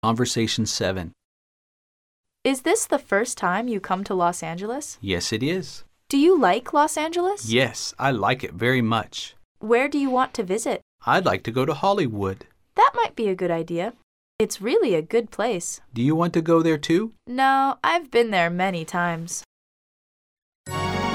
Conversation 7